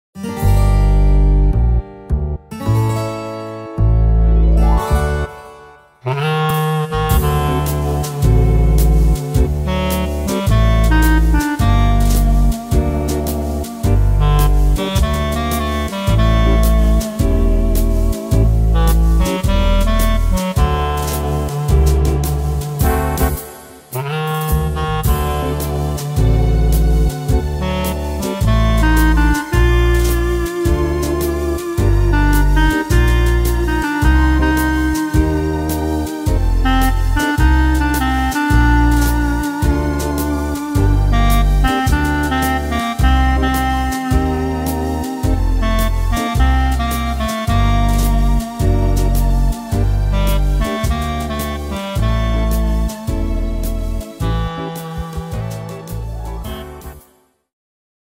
Tempo: 107 / Tonart: G-moll